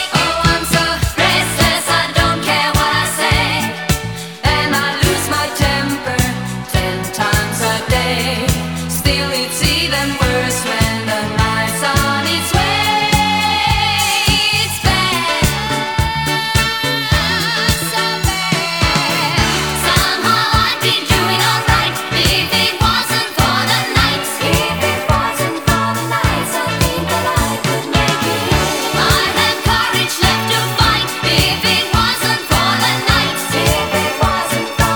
Жанр: Поп музыка / Рок / R&B / Танцевальные / Соул / Диско